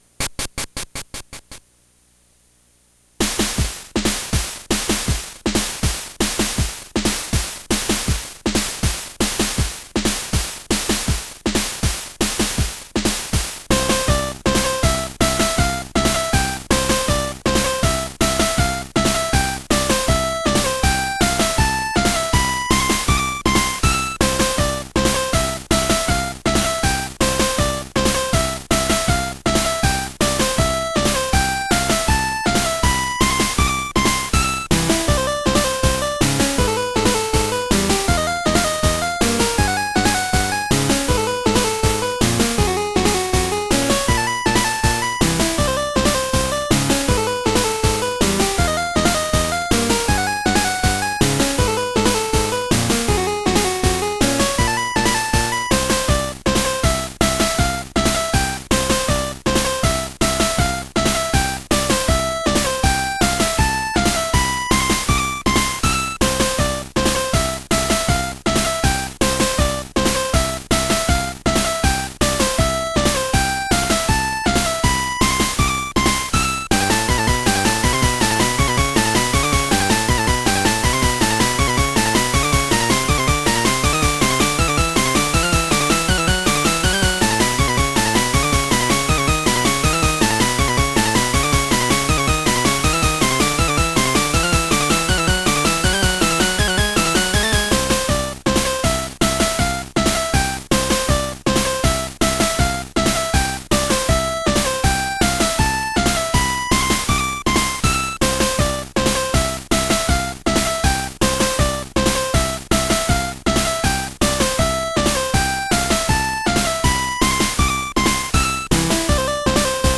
Threatening Foe - GAME BOY ORIGINAL MUSIC